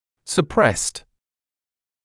[sə’prest][сэ’прэст]подавленный; заглушенный